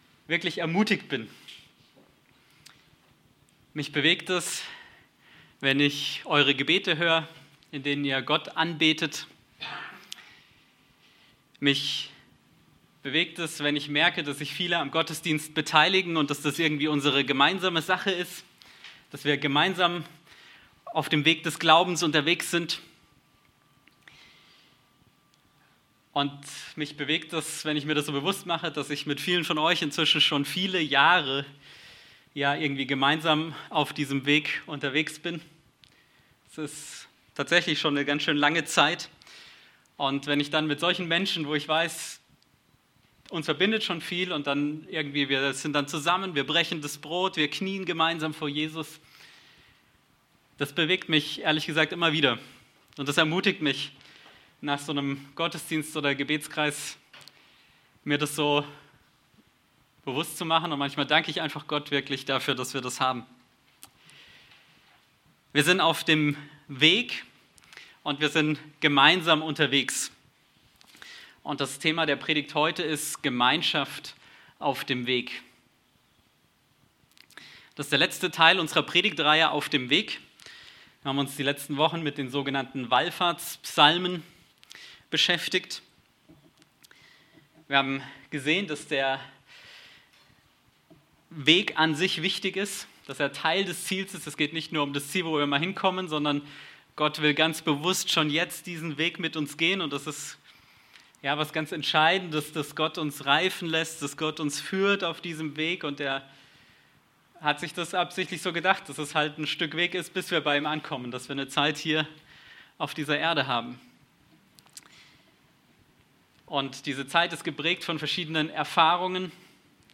Aus der Predigtreihe: "Auf dem Weg"